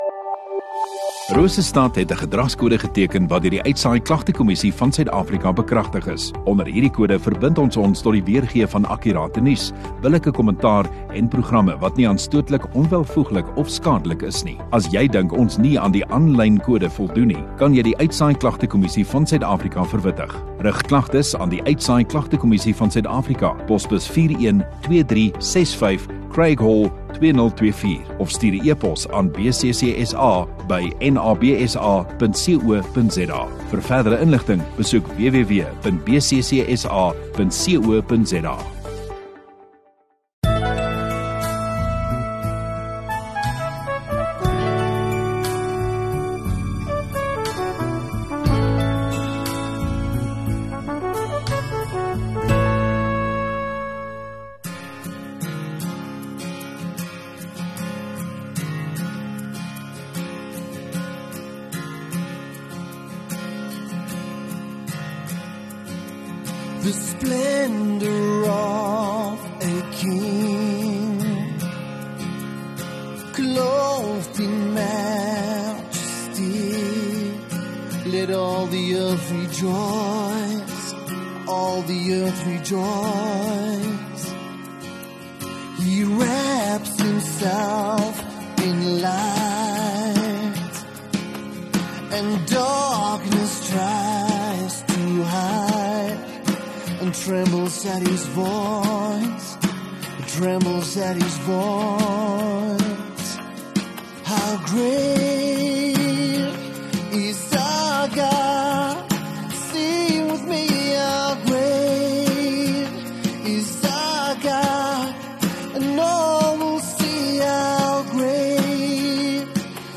View Promo Continue Radio Rosestad Install Rosestad Godsdiens 4 Apr Saterdag Oggenddiens